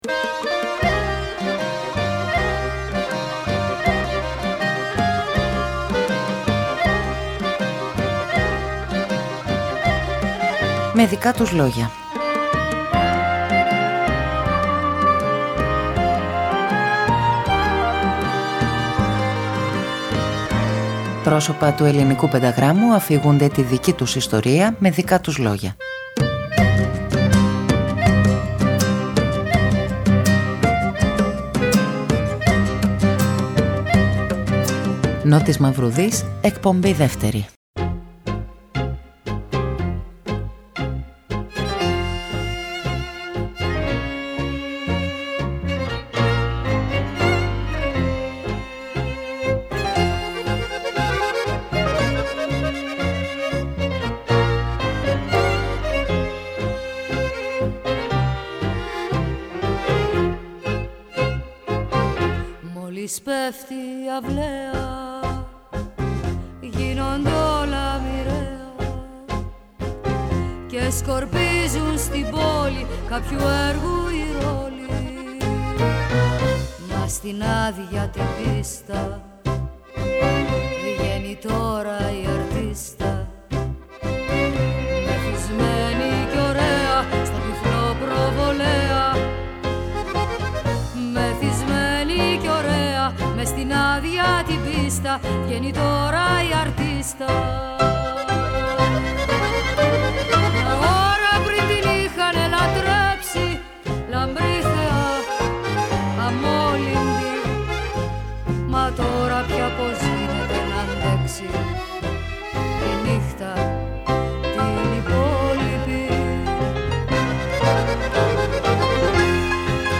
Πρόσωπα του πενταγράμμου αφηγούνται τη δική τους ιστορία…
Από την Κυριακή 3 Νοεμβρίου και για όλες τις Κυριακές του Νοέμβρη πίσω από το μικρόφωνο του Δεύτερου Προγράμματος της Ελληνικής Ραδιοφωνίας ο Νότης Μαυρουδής.